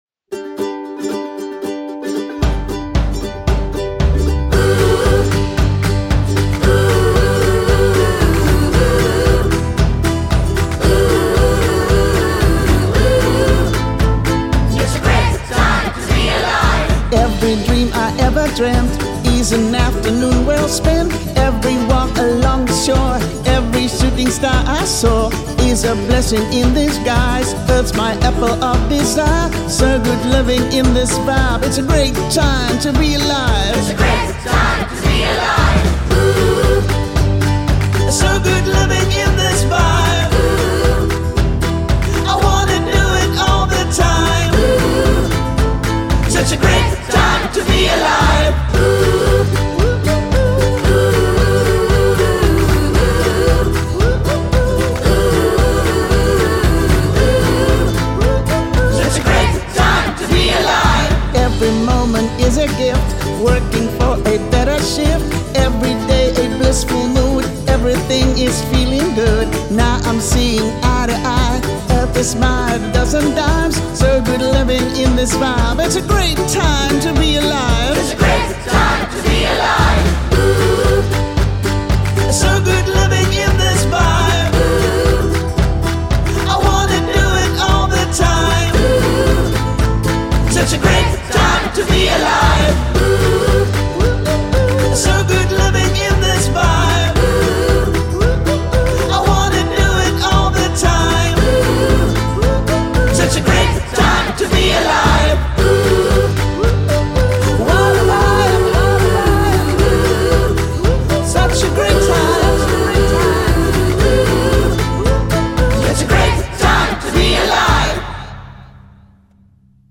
Mixed and Mastered by Big Tone Productions